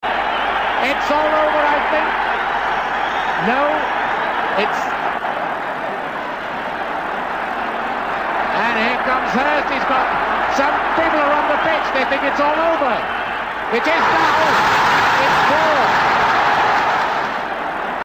The quote from Kenneth Wolstenholme's BBC TV commentary is one of the most popular sports soundbites in the world. In the final few seconds of the 1966 final, fans started invading the pitch, which didn’t stop the Three Lions from scoring one more goal, beating West Germany 4–2 and winning the World Cup.